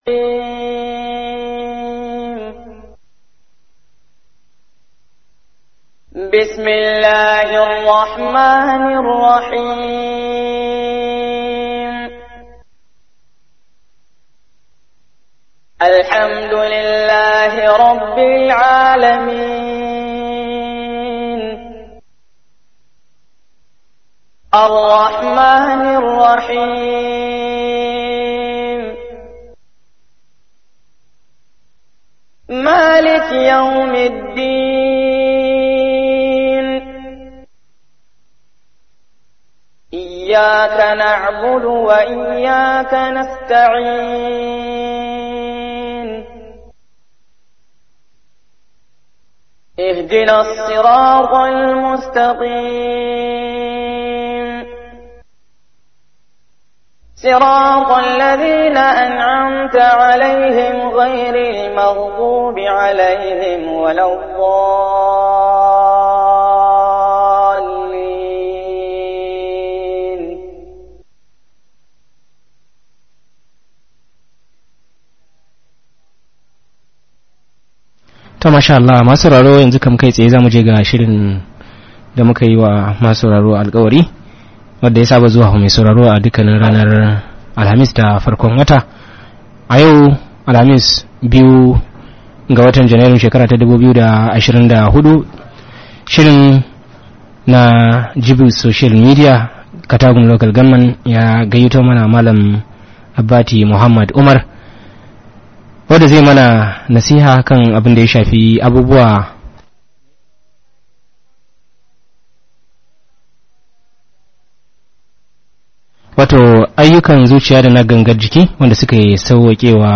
Ayyukan Zuciya Da Na Gangan Jiki Da Suke Sawwakewa Bawa Shiga Aljannah - Muhadara